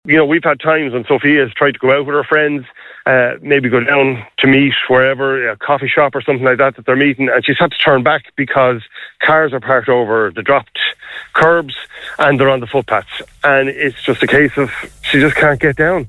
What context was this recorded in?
Speaking on Kildare Today